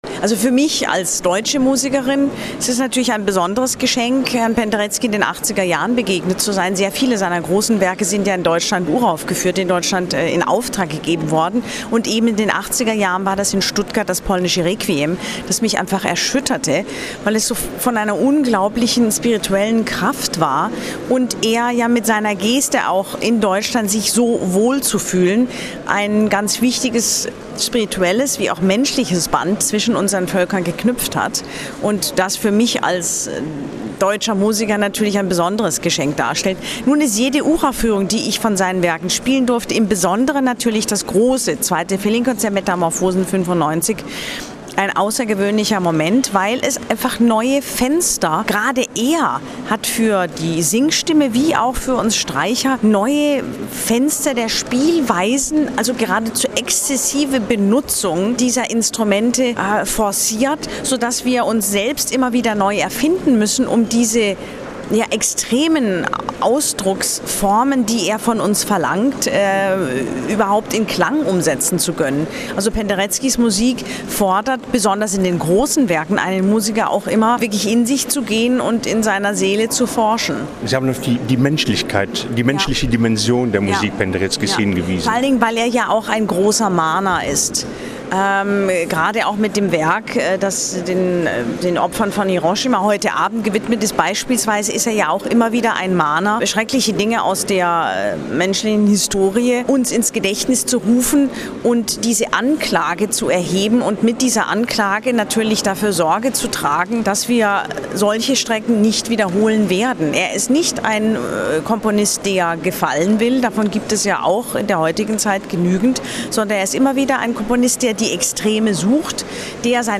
Unser eigenes Inerview mit A.S. Mutter finden Sie als Audiofile am Ende dieses Beitrags.